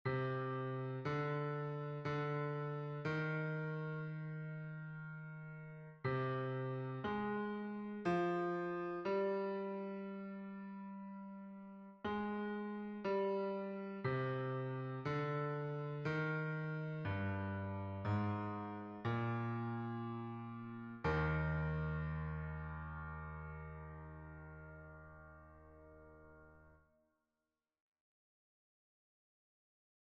Version piano
Basse Mp 3